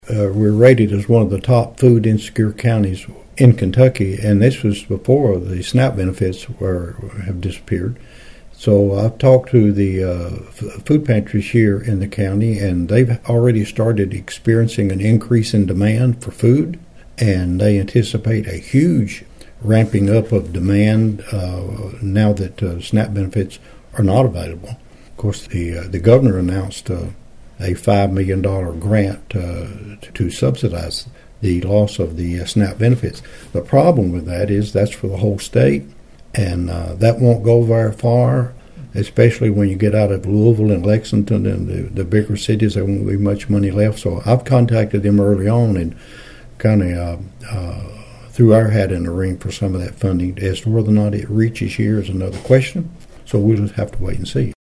Judge Martin told Thunderbolt News he was very concerned about growing food insecurities among the local residents.(AUDIO)